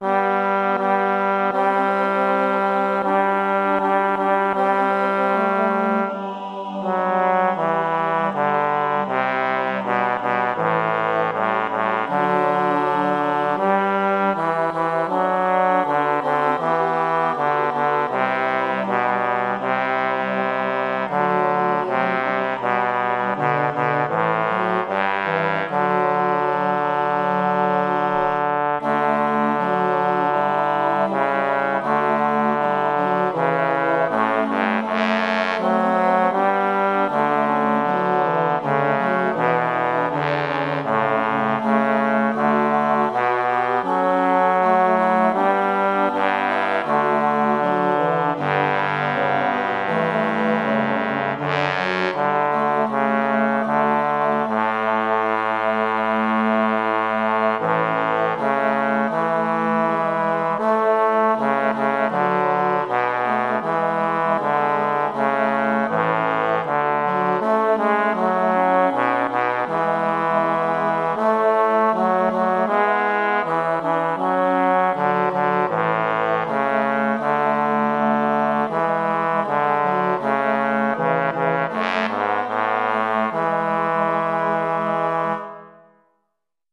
Jordens Gud bas
jordens_gud_bas.mp3